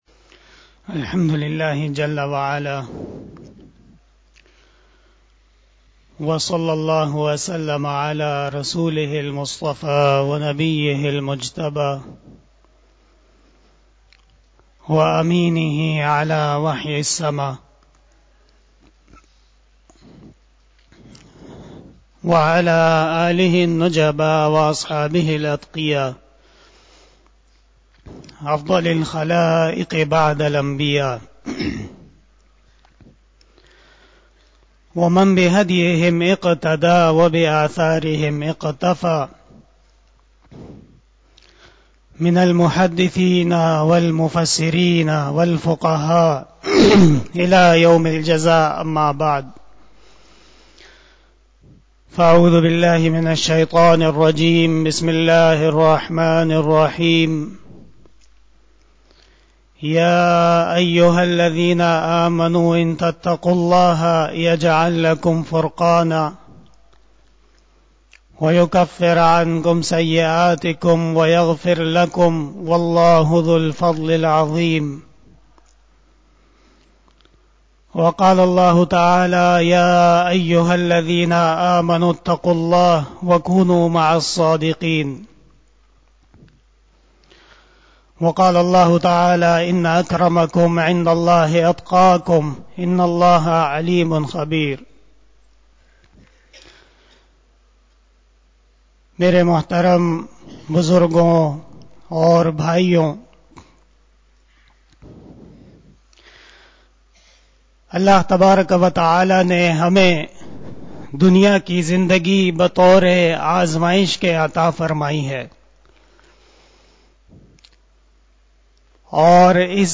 51 BAYAN E JUMA TUL MUBARAK 17 December 2021 (12 Jamadal Oula 1443H)
Khitab-e-Jummah